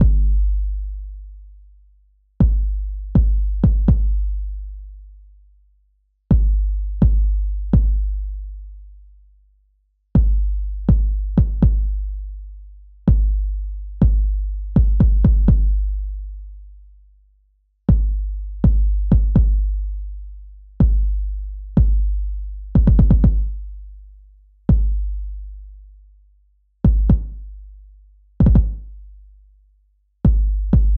Музыкальные биты для разнообразия